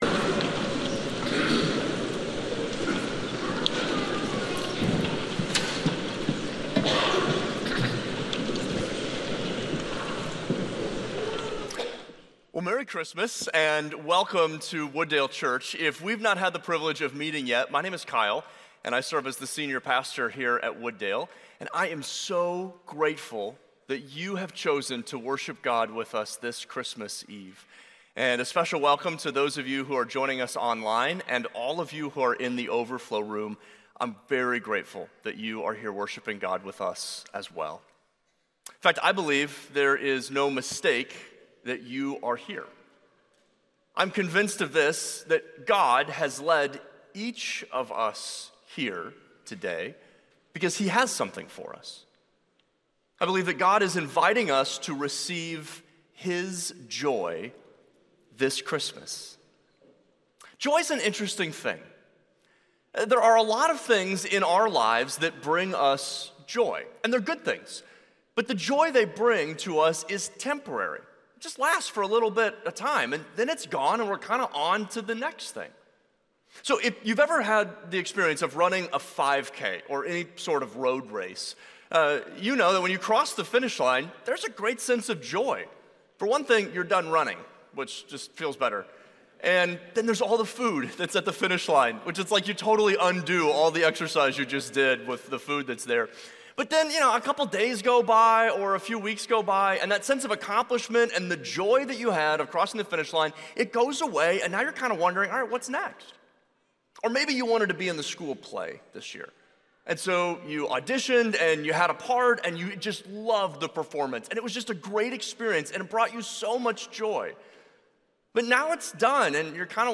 Share this Sermon Facebook Twitter Previous In Exile & Exodus Next Comfort Looking Back, Confidence Looking Forward View Series